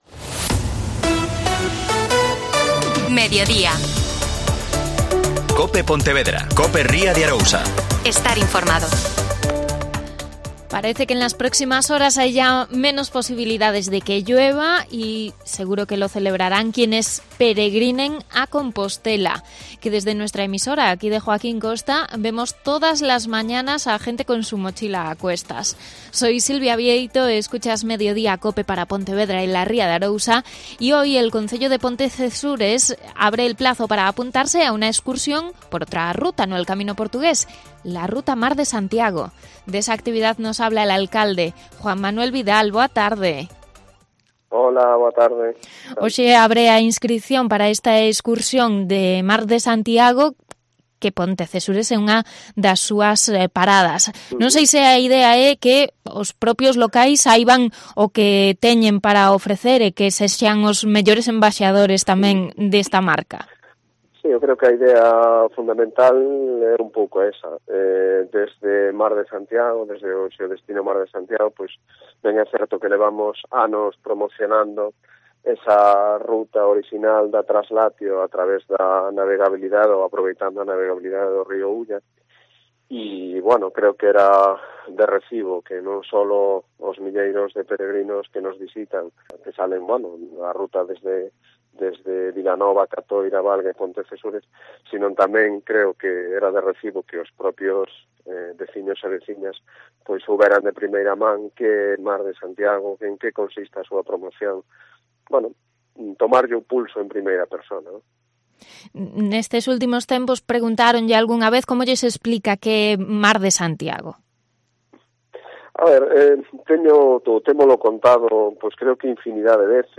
AUDIO: Juan Manuel Vidal, alcalde de Pontecesures